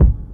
DrKick89.wav